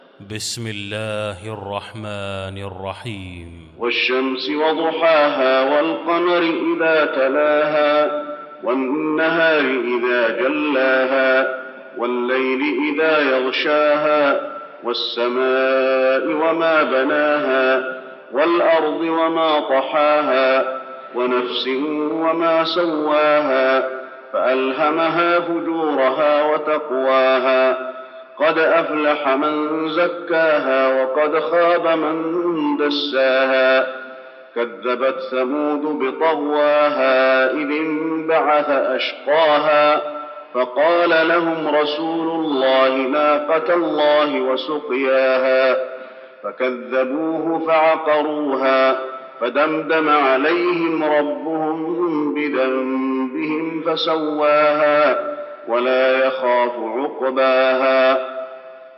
المكان: المسجد النبوي الشمس